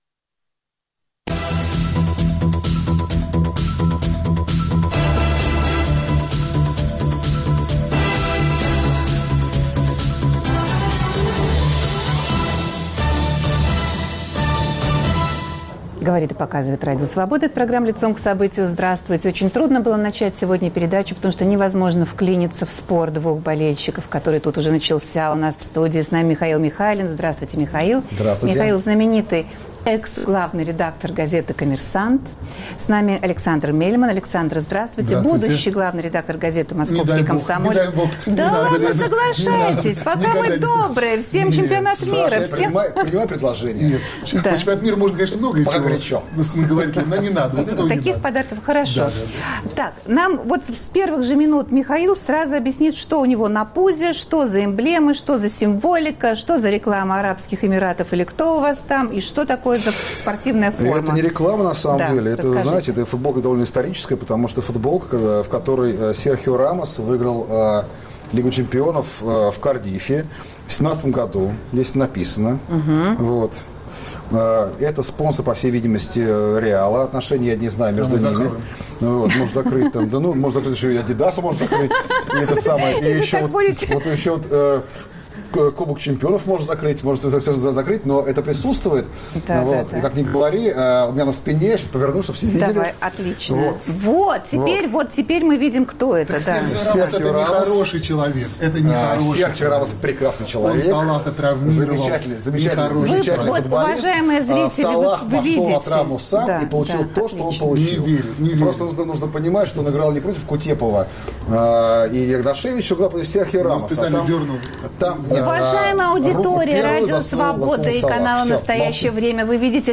В студии
На связи из Германии